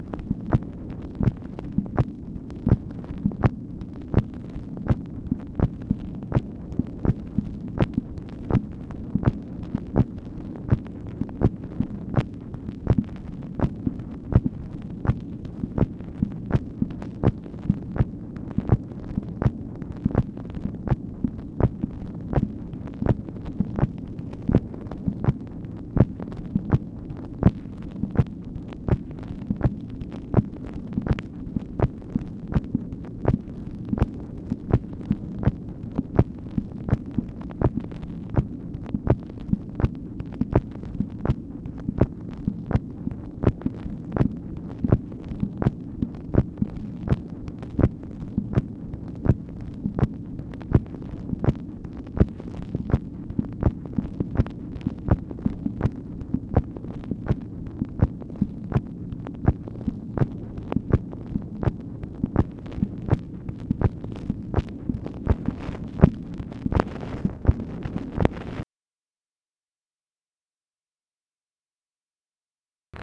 MACHINE NOISE, March 9, 1964
Secret White House Tapes | Lyndon B. Johnson Presidency